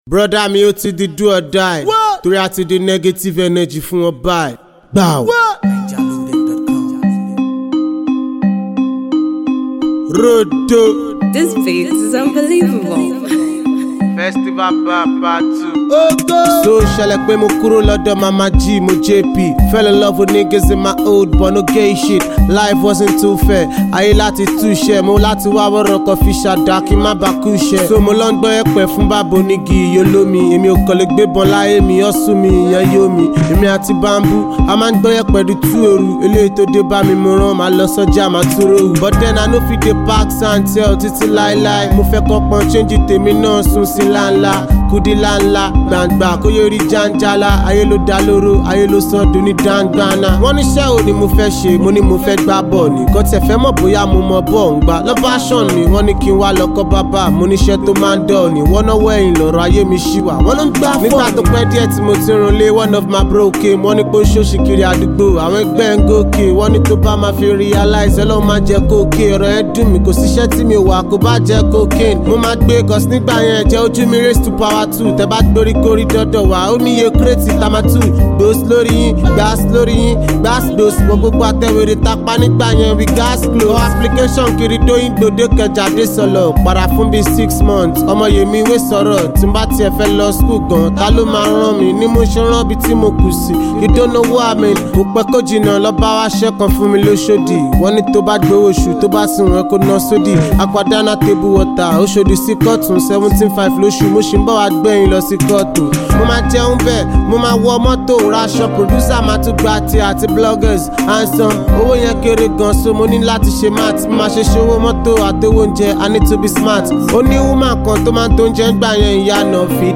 Nigerian talented rapper